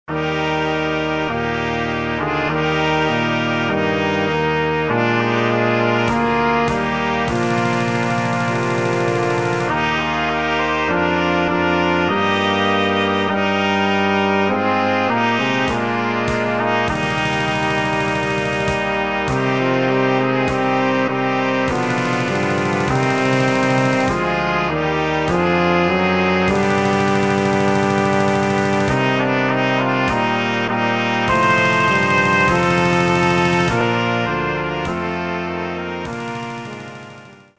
Opracowanie na septet blaszany i perkusję.
• 3 trąbki
• puzon
• tenor
• baryton
• tuba
• perkusja (obligatoryjnie)